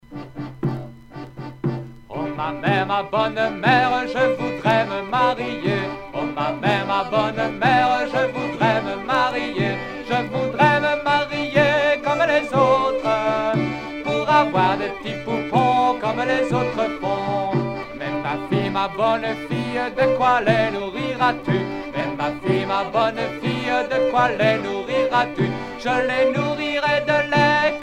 circonstance : fiançaille, noce ;
Genre dialogue